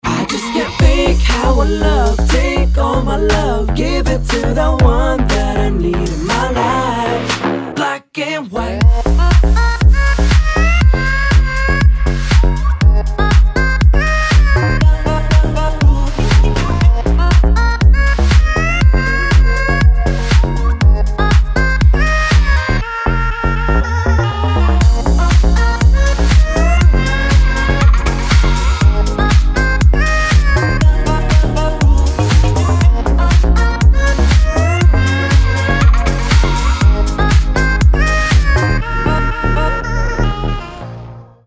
• Качество: 320, Stereo
мужской вокал
Electronic
club
Bass
house